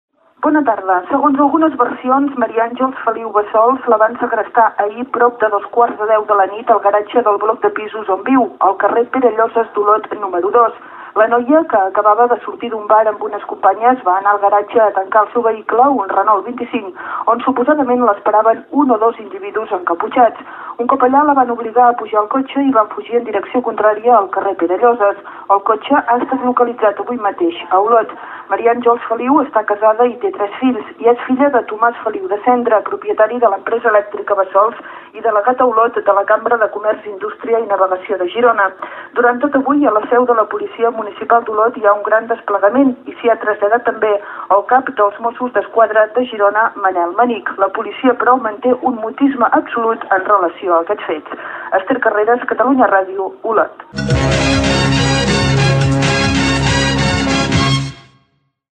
Informatius
Àudios: arxius sonors de Ràdio Olot i Catalunya Ràdio